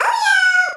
Mini Rosalina before starting a level.